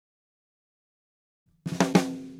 Snare Drum Fill 01.wav